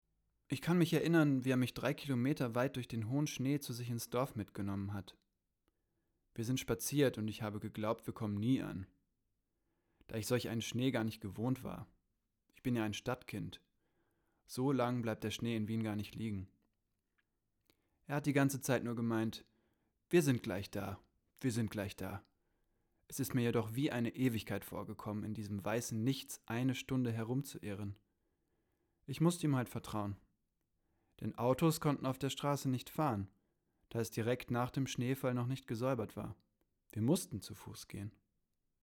Für die Website wurden die Textstellen nachgelesen: Einige von Jugendlichen und jungen Erwachsenen aus den Wohnhausanlagen, andere von Mitgliedern des Projektteams oder ausgebildeten Schauspielern.